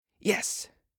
알림음 8_melody1.ogg